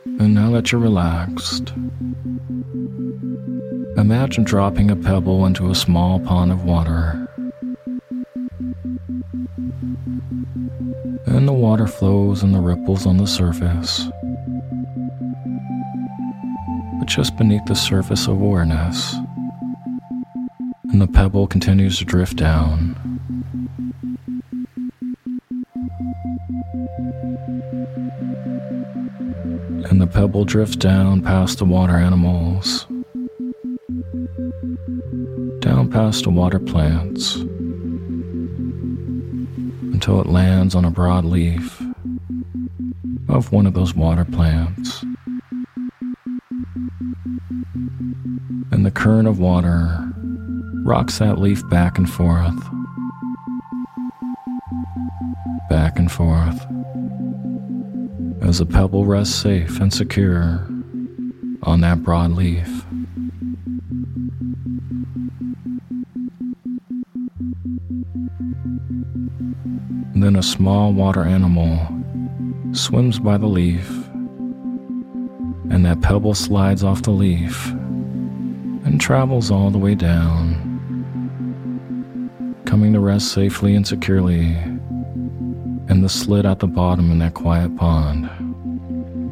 Sleep Hypnosis For Peacefulness In a Chaotic World With Isochronic Tones
In this guided meditation, you’ll be given mental imagery and positive suggestions to help keep yourself at peace and relaxed in this tough world.